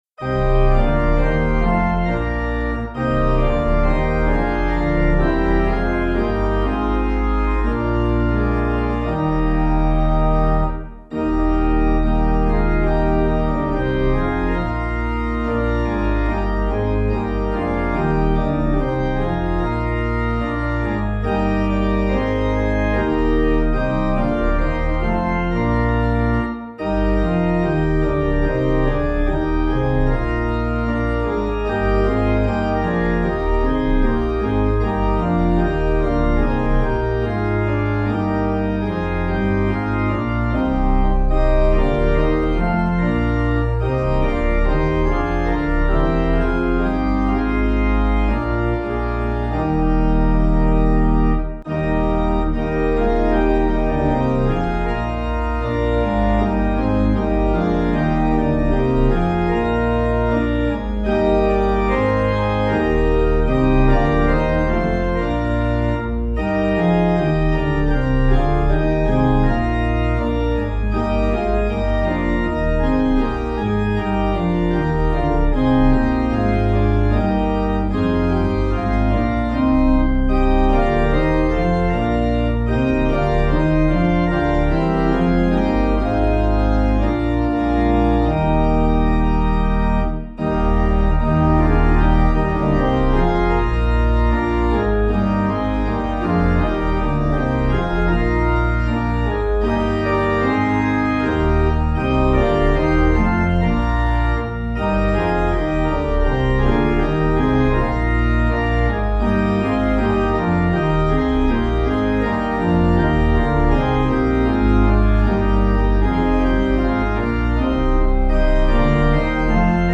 Practice singing the hymns for this Sunday’s worship services using the sheet music and audio accompaniment below.